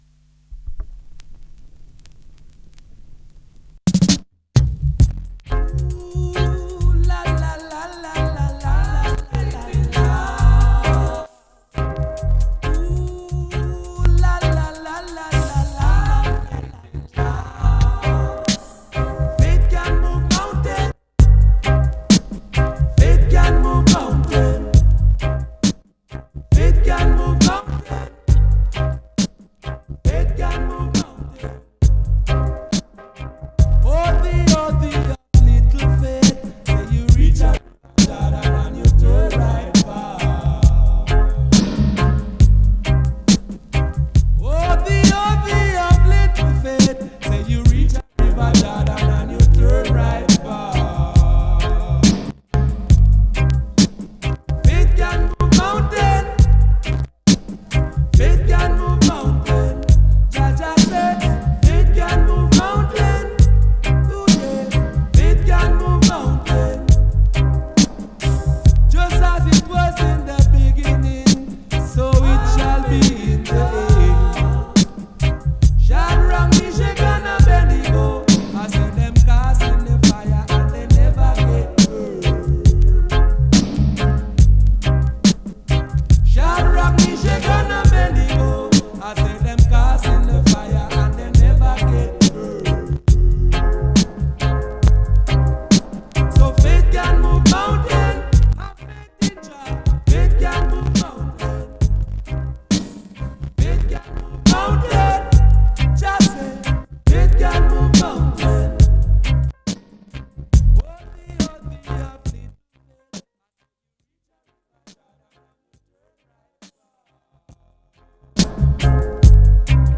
Special Dub Selection